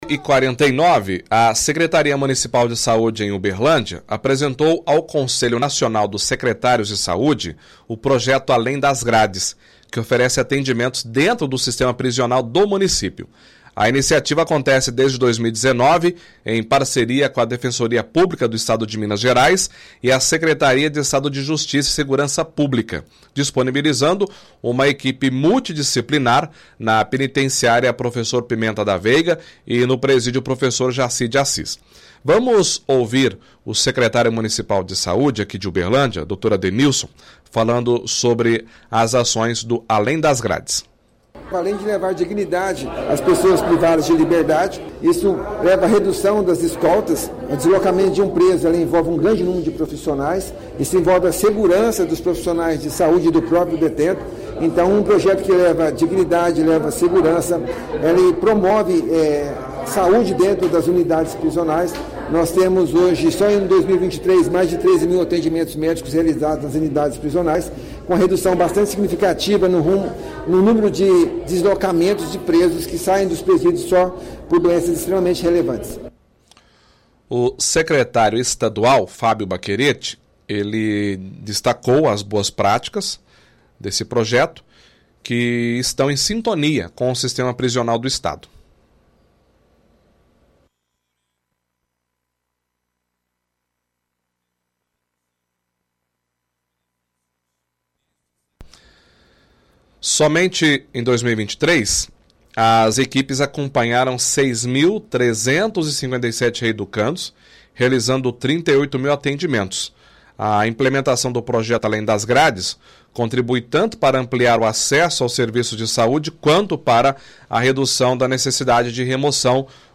Além das grades* – Entrevista secretário municipal de Saúde, Adenilson Lima, levar dignidade das pessoas privadas de liberdade, segurança e promover saúde dentro das unidades prisionais; – Entrevista secretário de Estado de Saúde de Minas Gerais, Fabio Baccheretti, vê essa política sendo bem feita, essas boas práticas tem que ser expandidas.